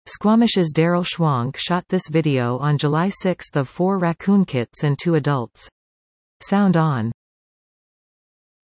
Video: See and hear these raccoon kits - Squamish Chief